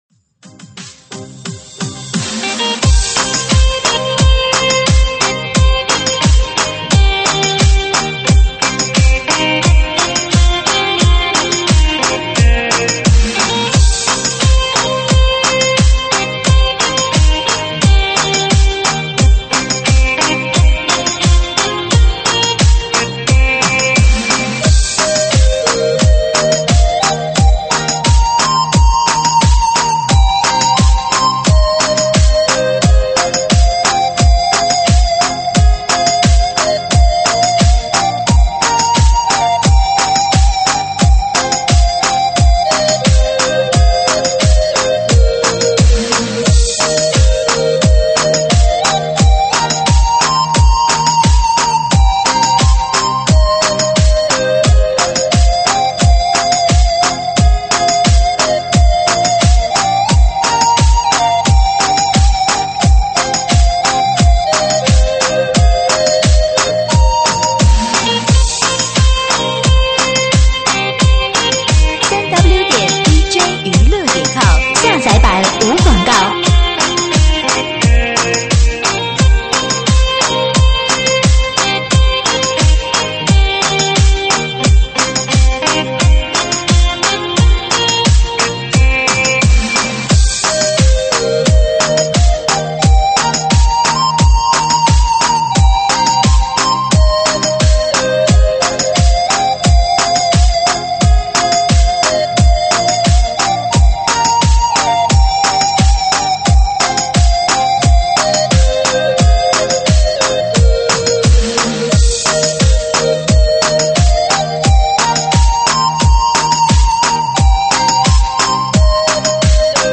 舞曲类别：电子琴